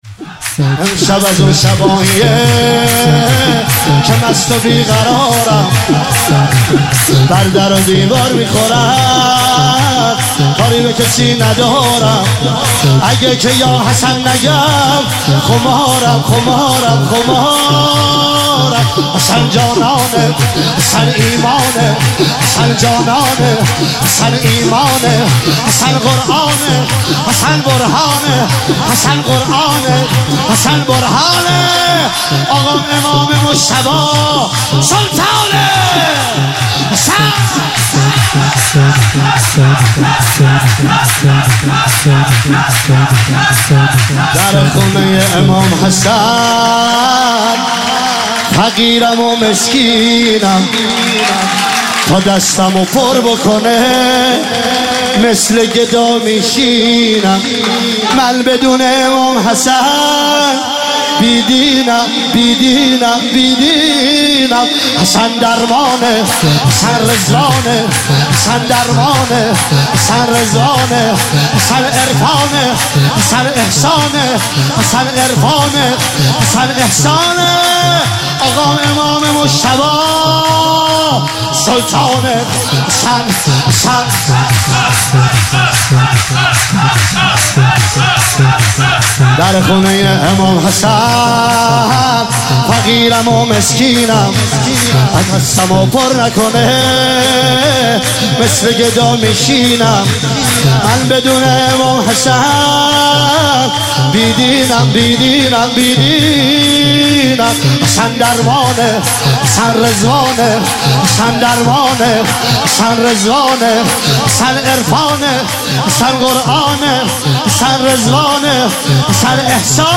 عنوان جشن ولادت امام حسن مجتبی علیه السلام – شب پانزدهم ماه مبارک رمضان ۱۳۹۸
سرود-شور